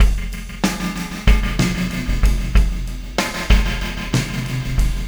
Pulsar Beat 13.wav